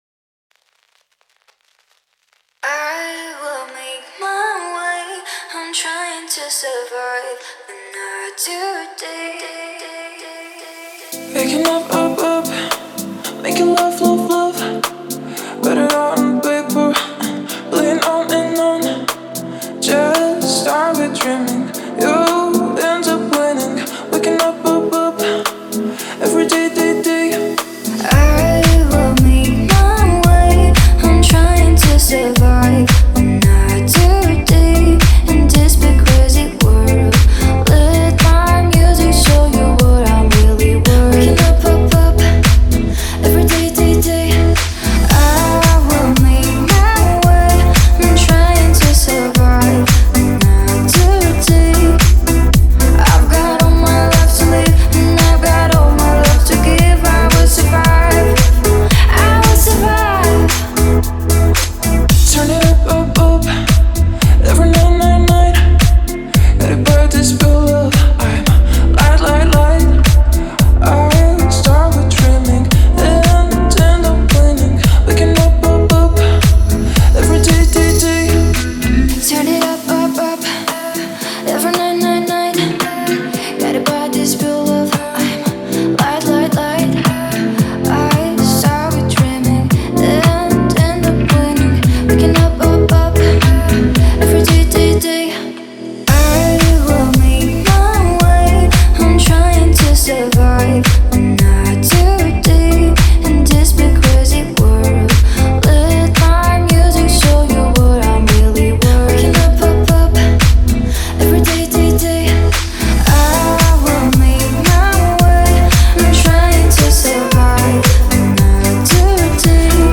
پاپ و ایندی پاپ